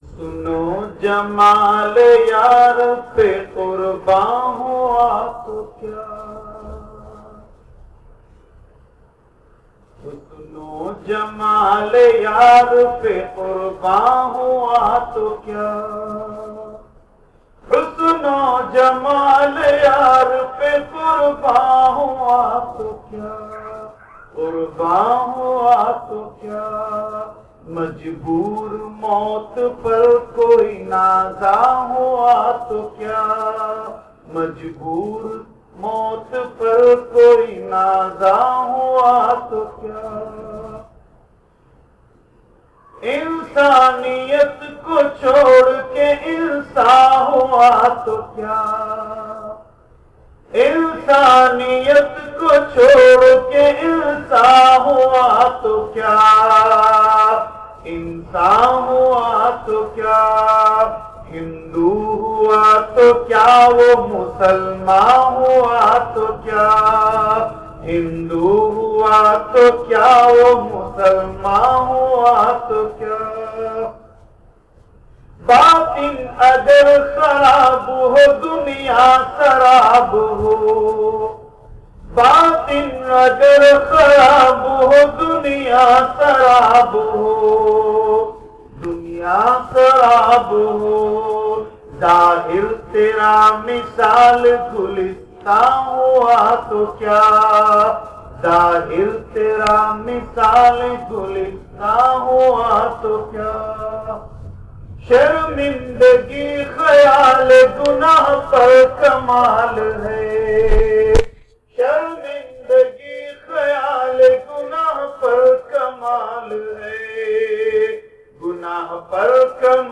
Taleemat Silsila e Qadria Chishtia Kamaliya, Tajweed ul Quran Educational and Charitable Trust, Amberpet Hyderabad, Every Monday After Isha.